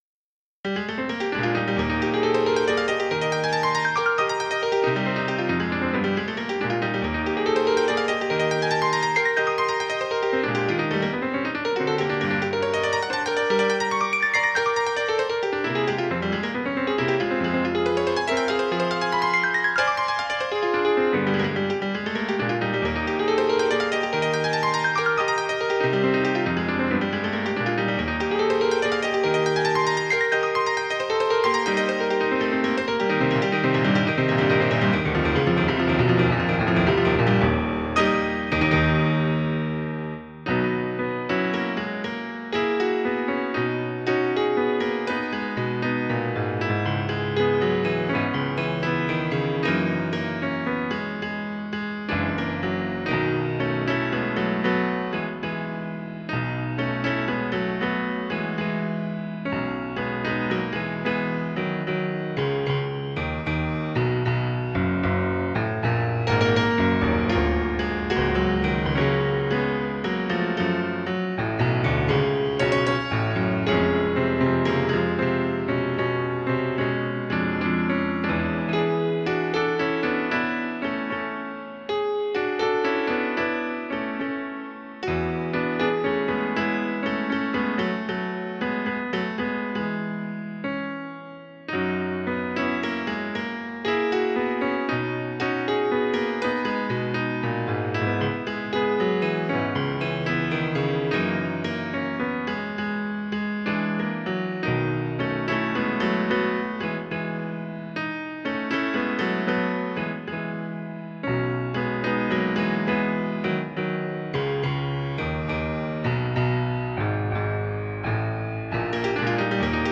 pianoforte
romanticismo
scherzo
sonata